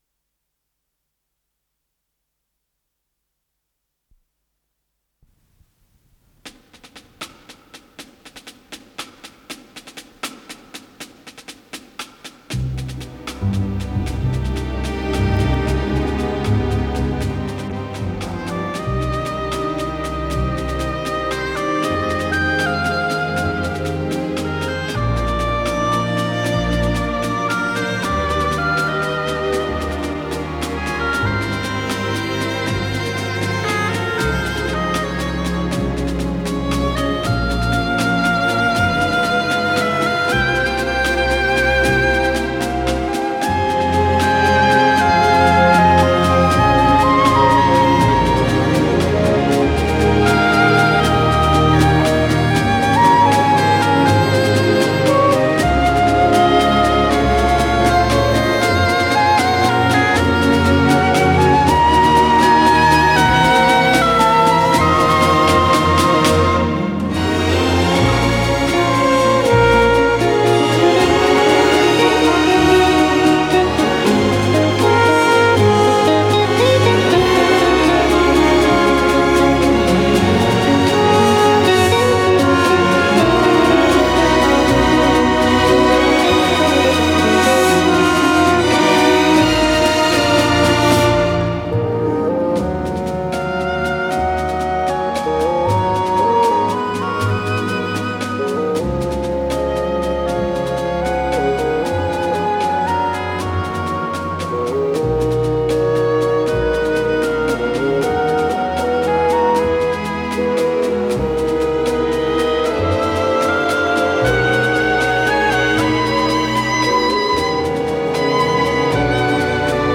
ПодзаголовокДля симфонического оркестра и электрогитары
ВариантДубль моно